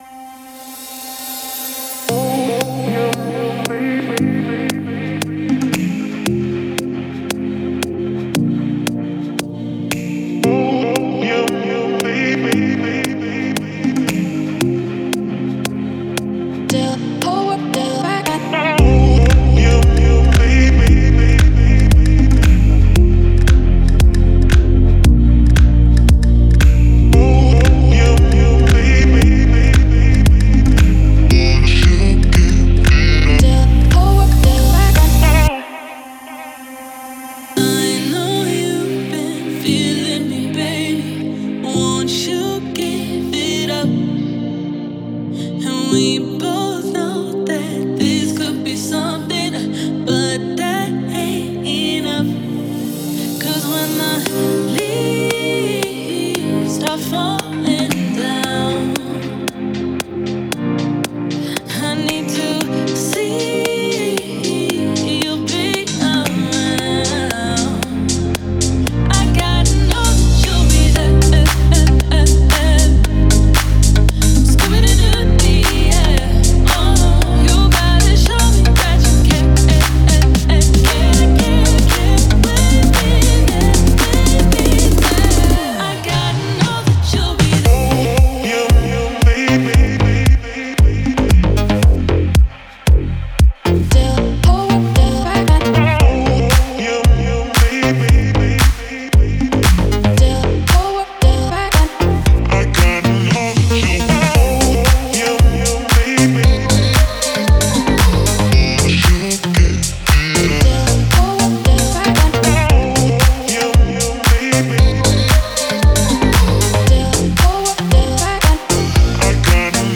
энергичная композиция в жанре поп-электроника